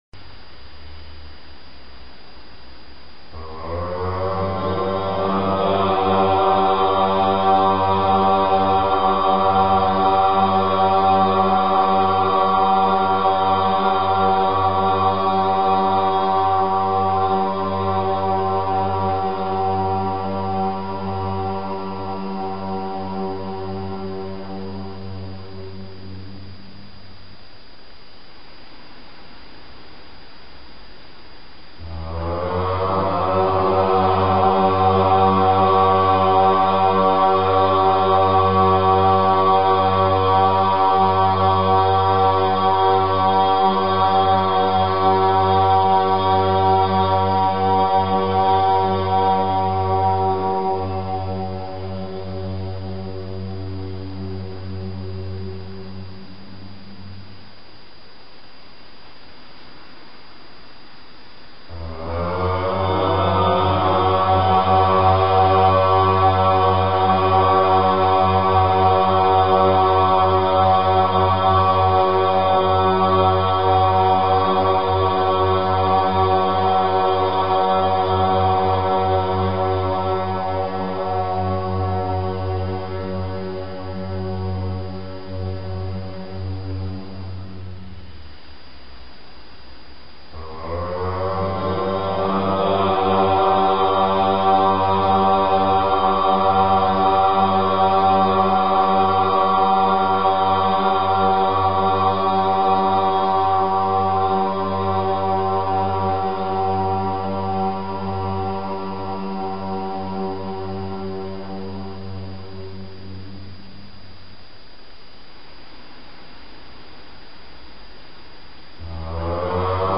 OM 108 times_(new).mp3